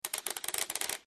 dicenormal.mp3